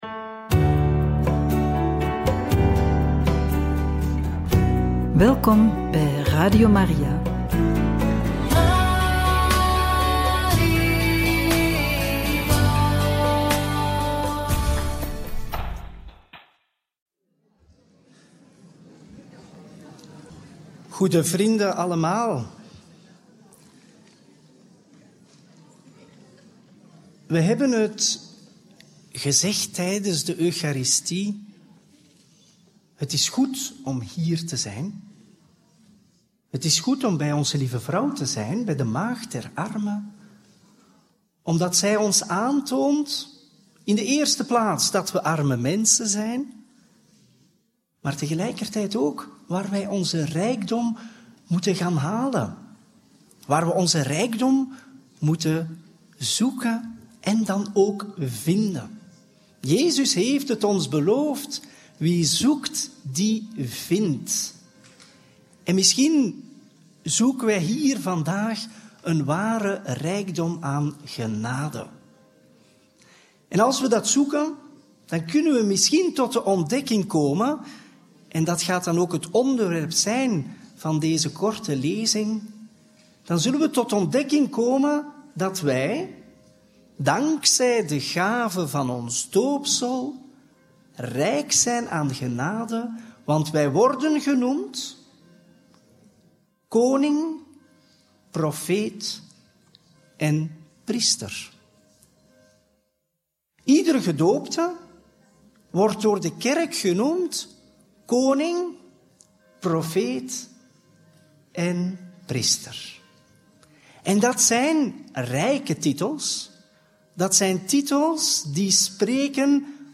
conferentie
op onze bedevaart in Banneux – Radio Maria